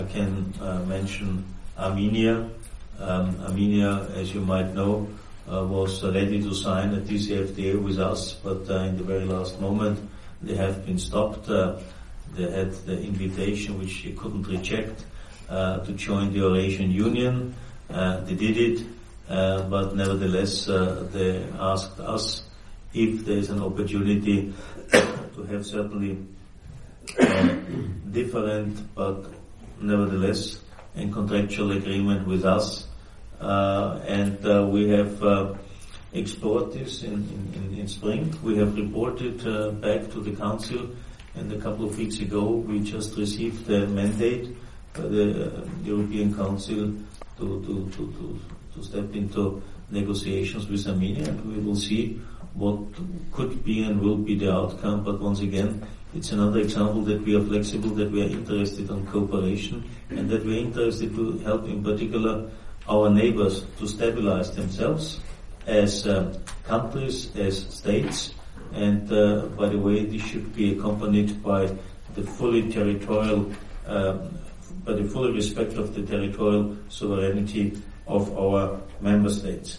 Եվրամիության հետ ասոցացման համաձայնագիր ստորագրելուց հրաժարված պաշտոնական Երևանը Բրյուսելի հետ մերձեցման փորձեր է անում արդեն որպես Կրեմլի նախաձեռնած Եվրասիական տնտեսական միության անդամ։ ԵՄ ընդլայնման և հարևանության հարցերով հանձնակատար Յոհանես Հանը այսօր Բրյուսելում լրագրողների հետ հանդիպմանը հիշեցրել է, որ փաստաթուղթը, որի շուրջ կողմերը պատրաստվում են բանակցել, Հայաստանը պետք է նախաստորագրեր 2013-ին Վիլնյուսում։
Ստորև ներկայացնում ենք Յոհանես Հանի հայտարարության ձայնագրությունը և թարգմանությունը: